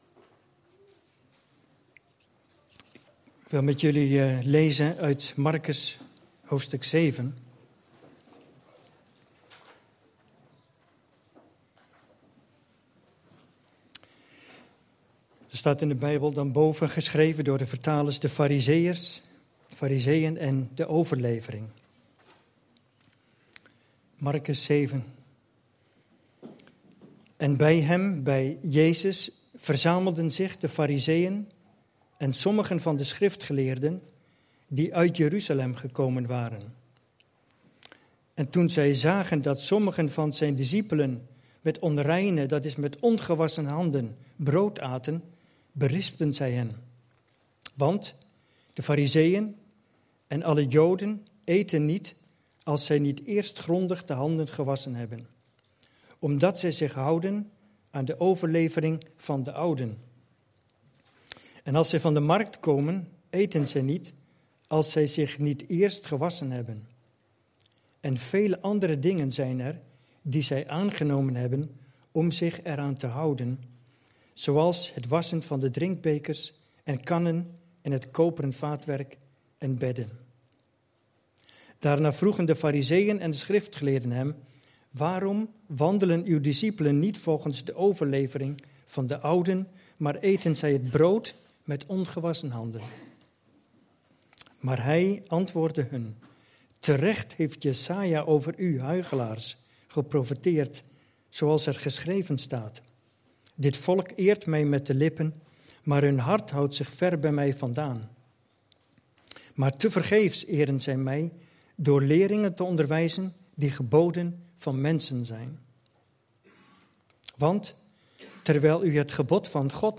Preken – Christengemeente Midden-Limburg